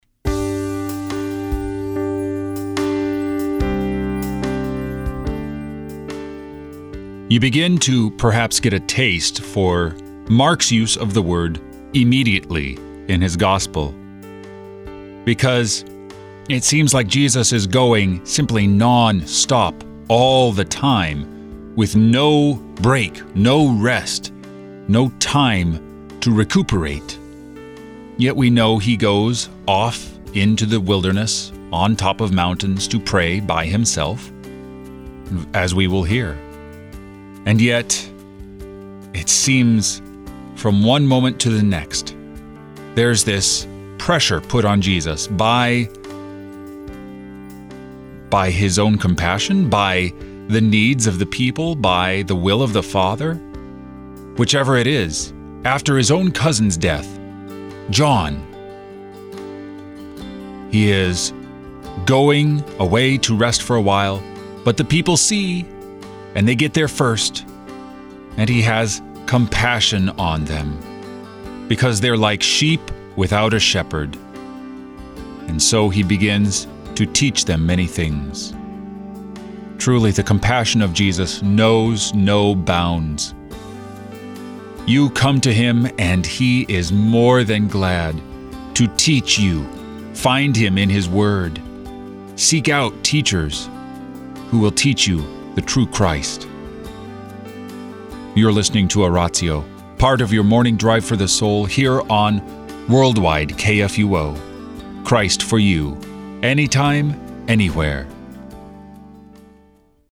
Morning Prayer Sermonette